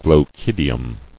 [glo·chid·i·um]